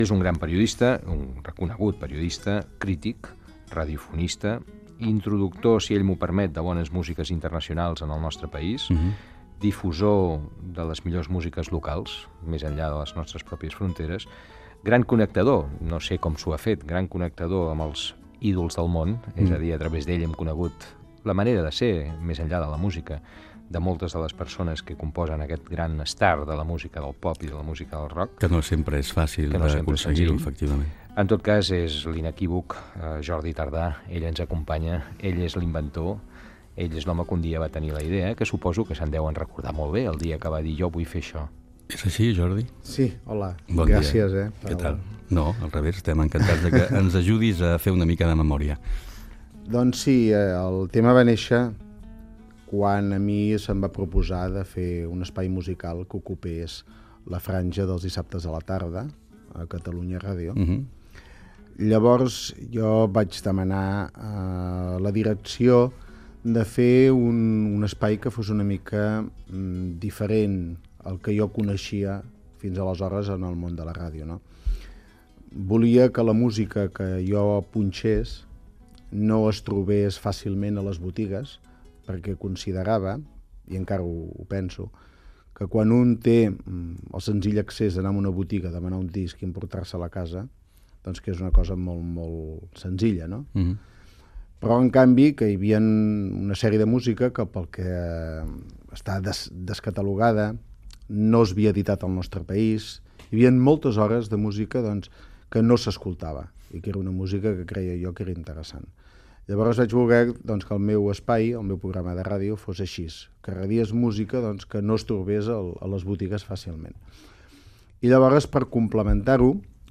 Fragment d'una entrevista